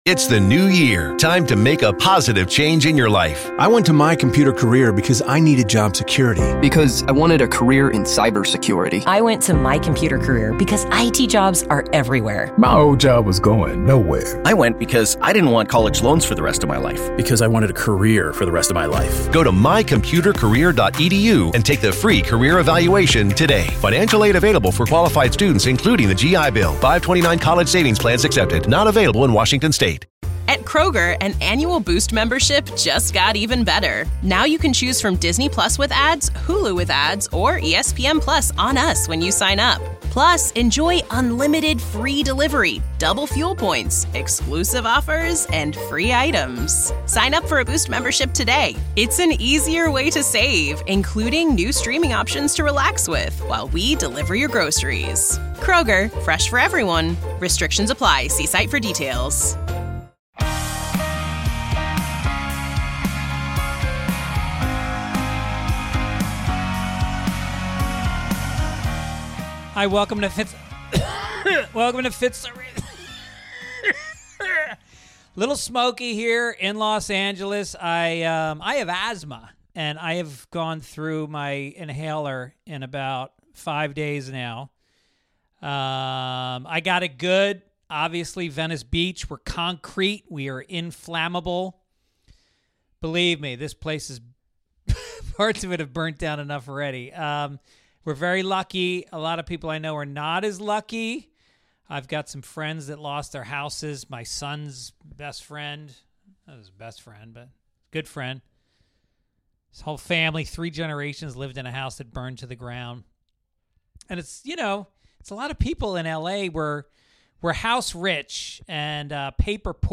This week I'm sharing the workshop that I presented at the EPIC Community annual conference in December. This is an interactive workshop where we talked through optimizing our health, wellness and performance and taking our lives to a new level in the coming year.